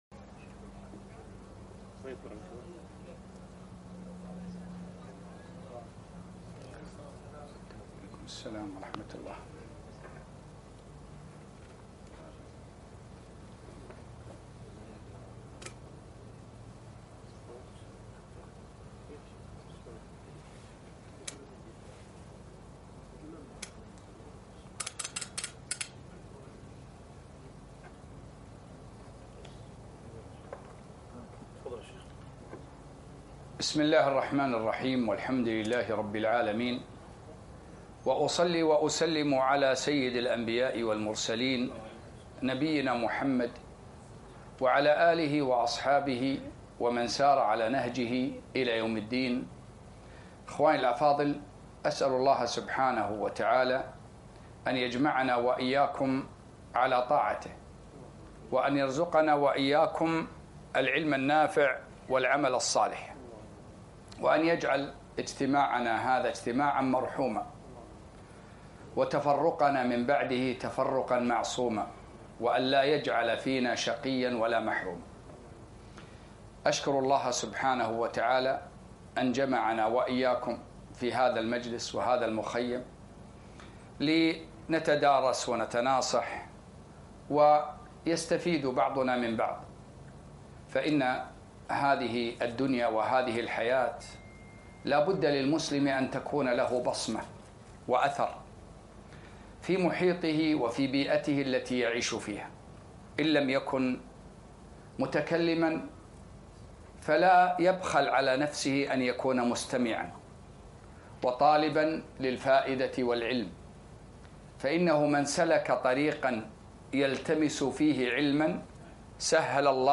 محاضرة - دولة المرابطين دروس و عبر